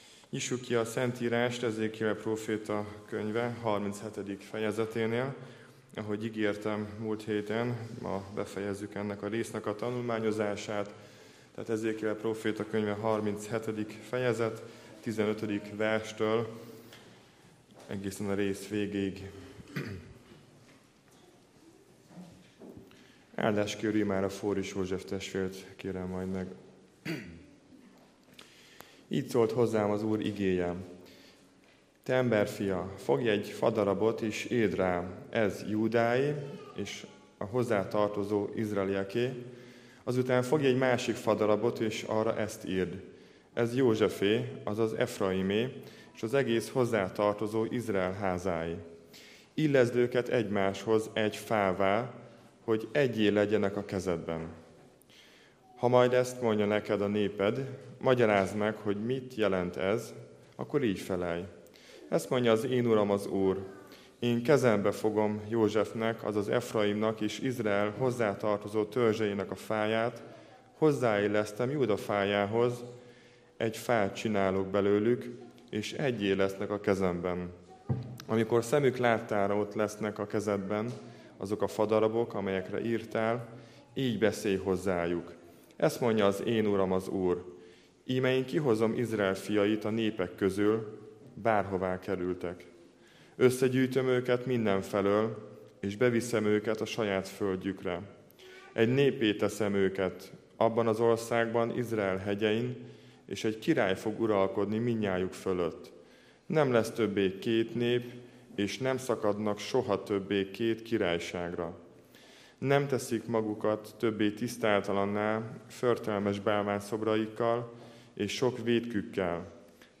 Székesfehérvári Baptista Gyülekezet Igehirdetések